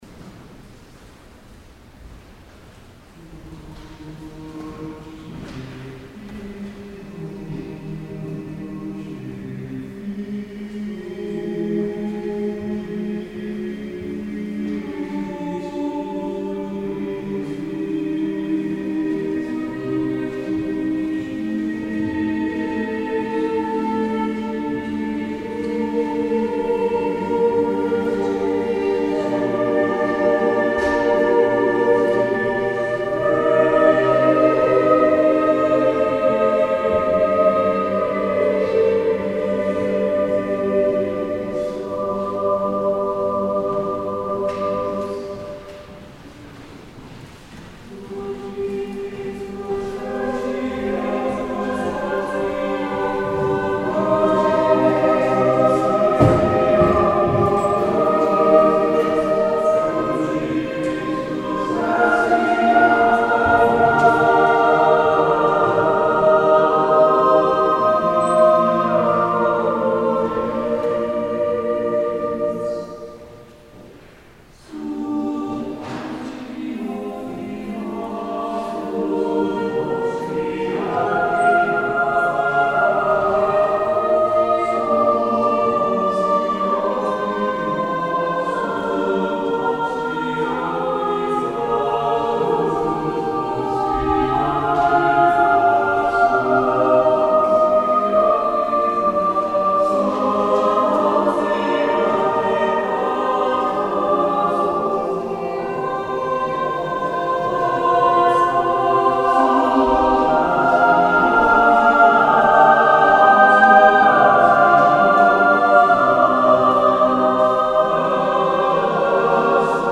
Christus factus est in four voices, composed by Felice Anerio of the Roman School, disciple of St. Philip Neri